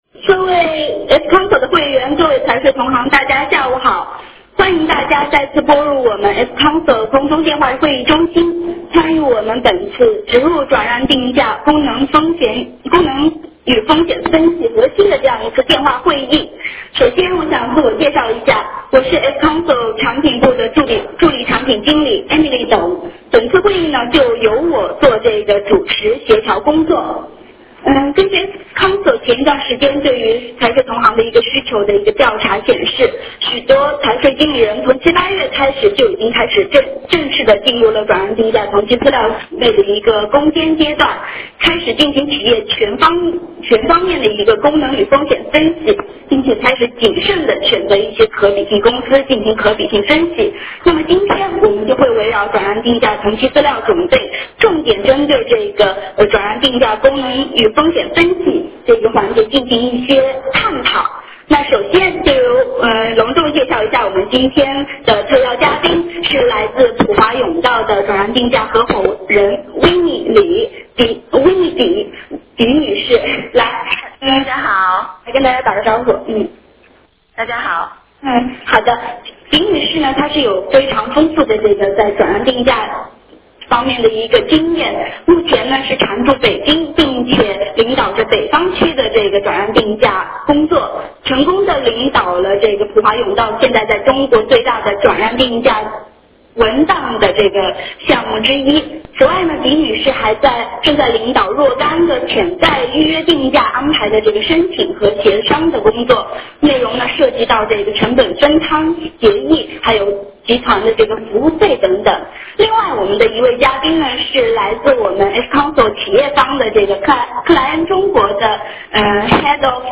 电话会议
16：00 问答互动环节